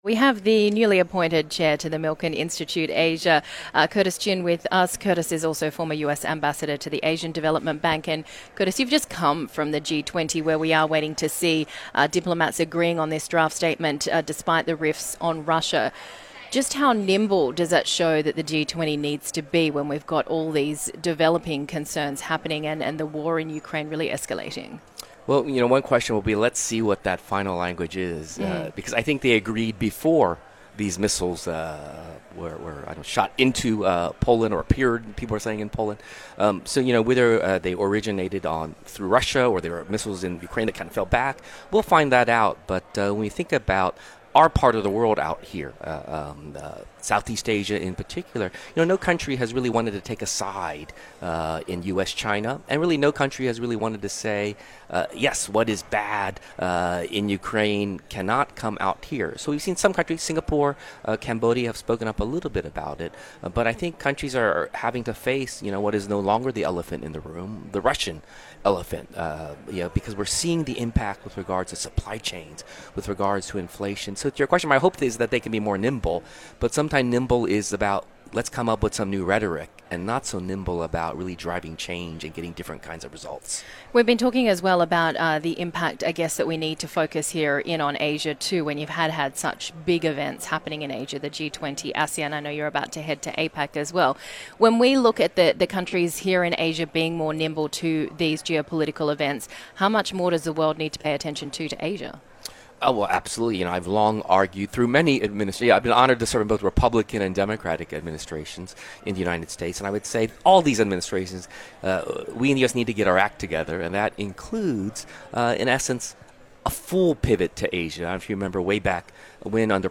Curtis S. Chin on Asia and Global relations (Radio)